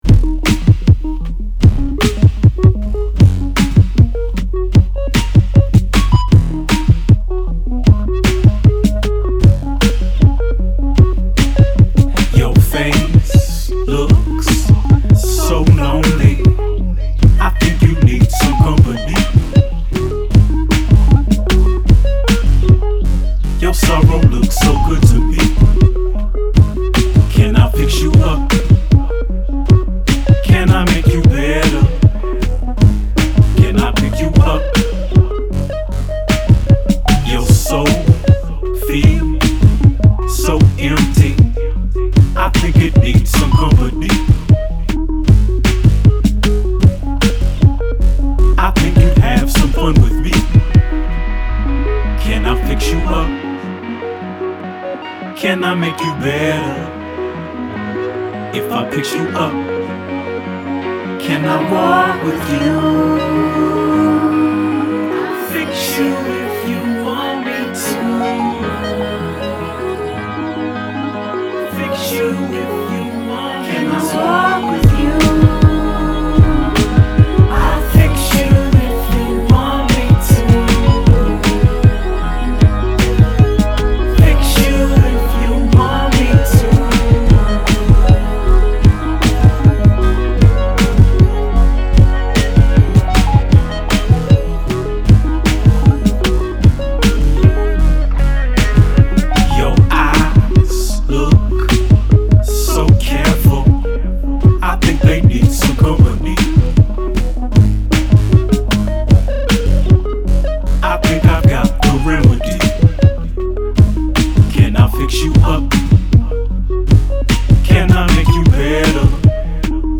21st century synth pulse